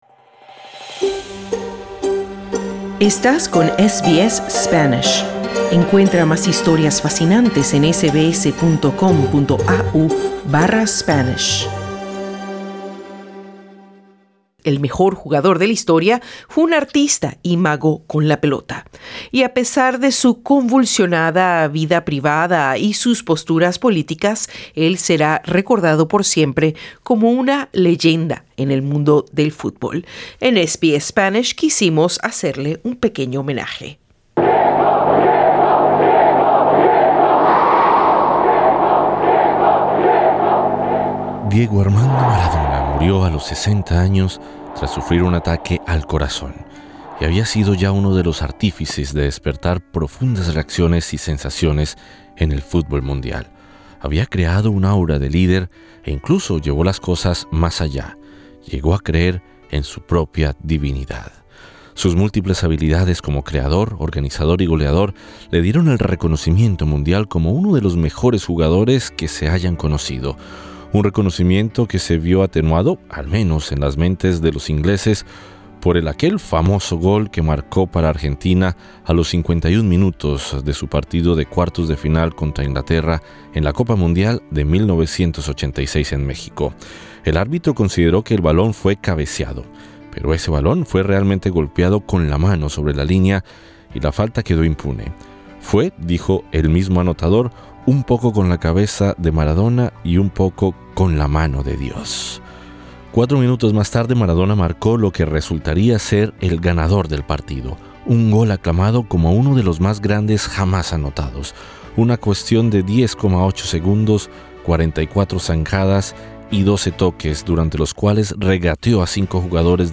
En SBS Spanish le quisimos rendir un homenaje con diferentes voces tanto en Australia como en Argentina, su tierra. Escucha las sensaciones y opiniones de importantes referentes del deporte en Australia y de personas de la comunidad, haciendo clic en la imagen de portada.